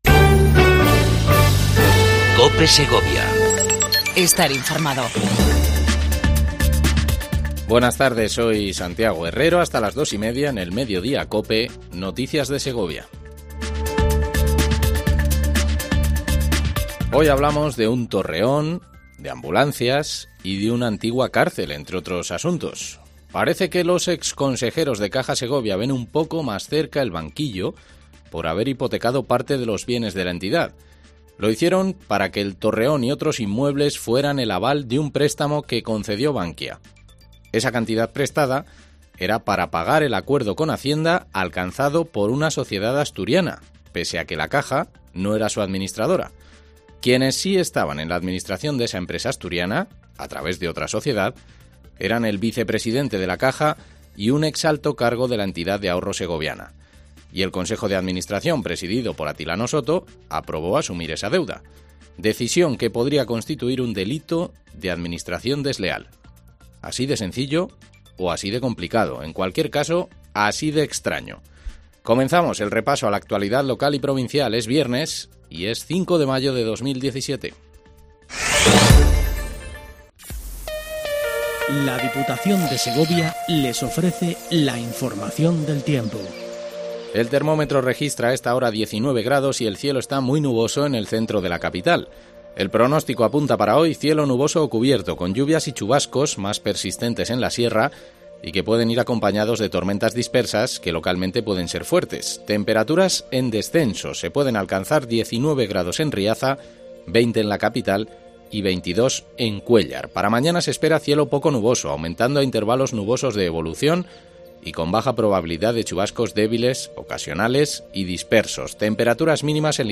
INFORMATIVO MEDIODIA COPE EN SEGOVIA 05 05 17